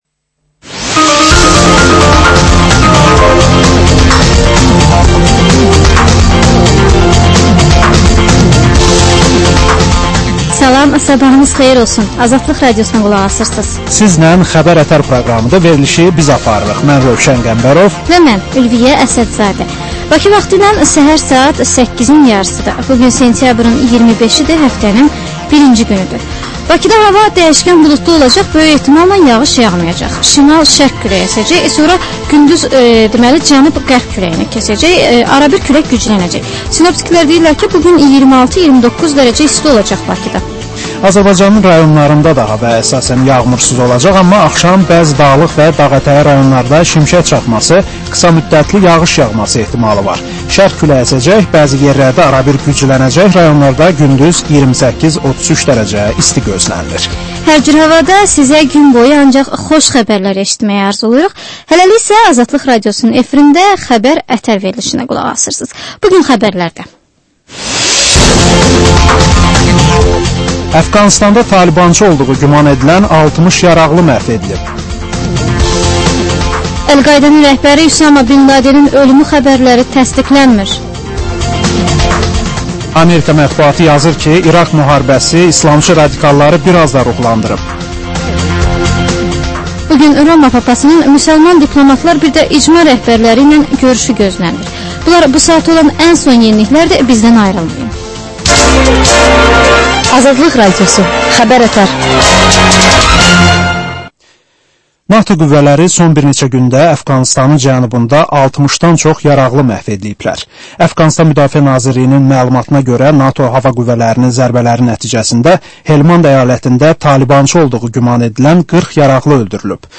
Səhər xəbərləri